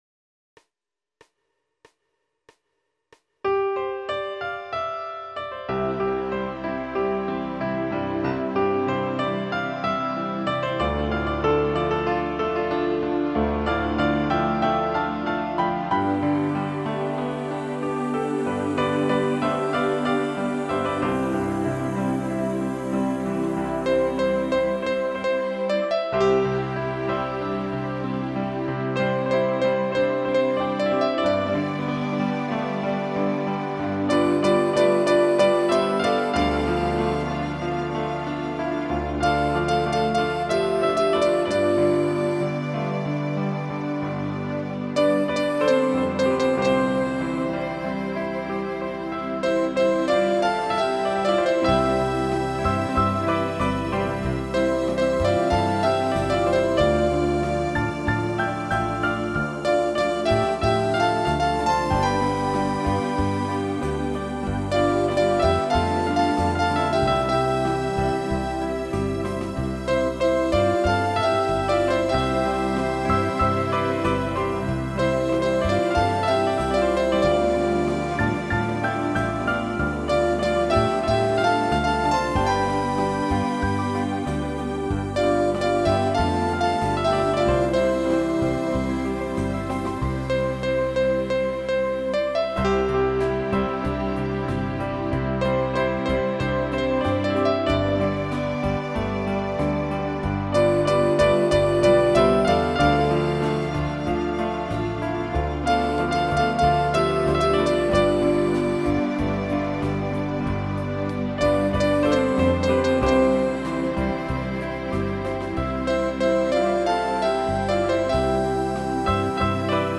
1/  Jemné melódie